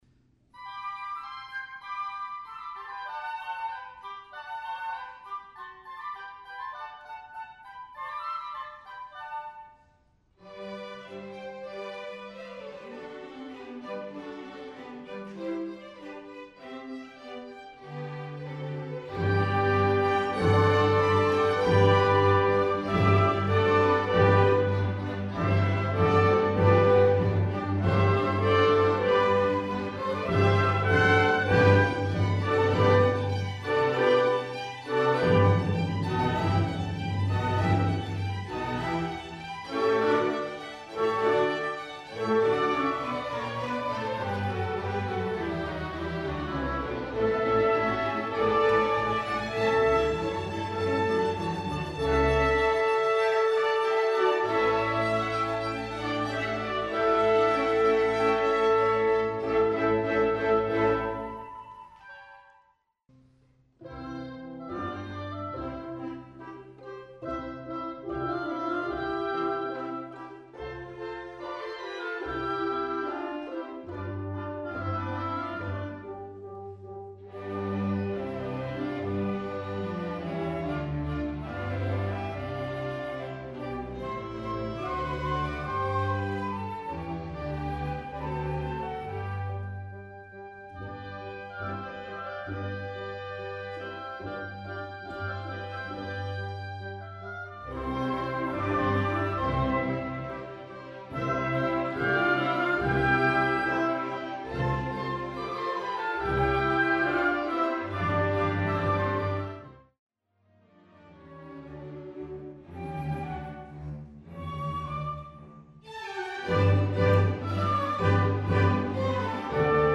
[GASP] = Great Audio Sneak Preview from rehearsal on April 16, 2007 - 4 more rehearsals... (:-)
Symphony No. 100 in G Major
I   Adagio - Allegro
IV  Finale: Presto
Military_rehearsal_clip.mp3